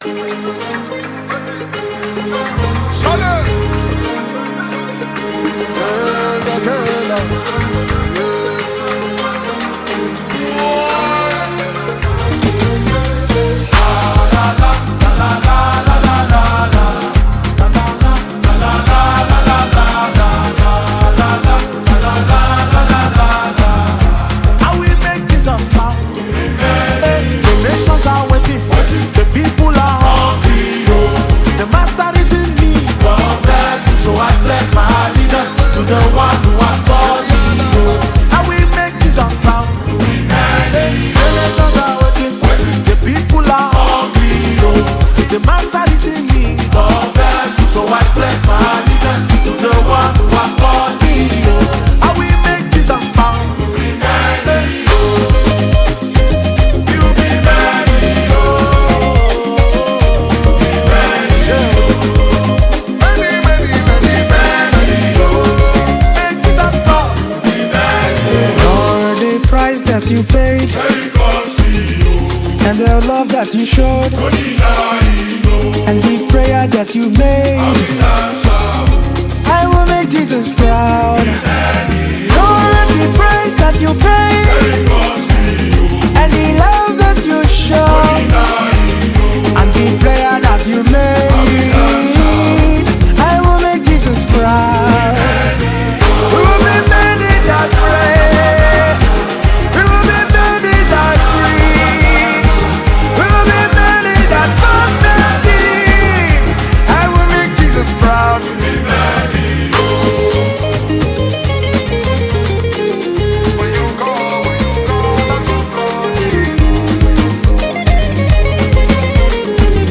Nigeria Gospel Music
soul-stirring worship song